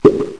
coinin1.mp3